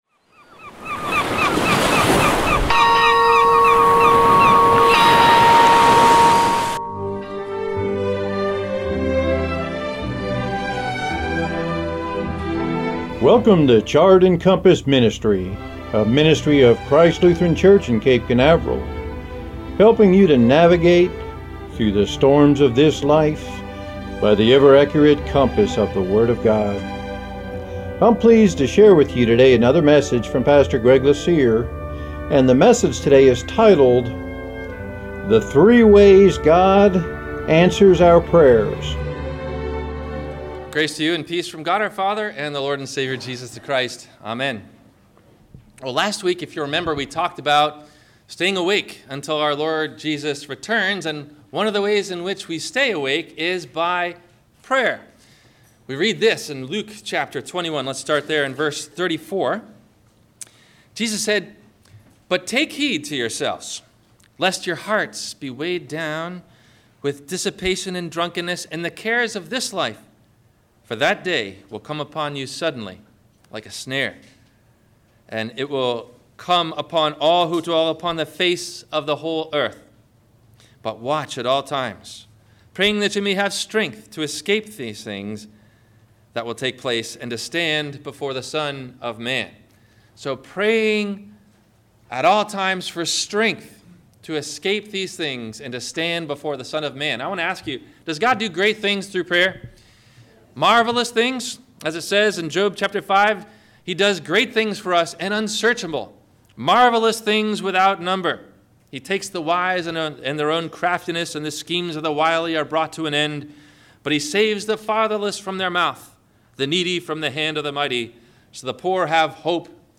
Questions asked before the Radio Message: